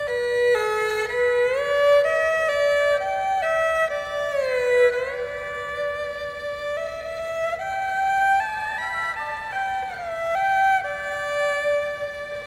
Audición de diferentes sonidos de la familia de cuerda frotada.
Erhu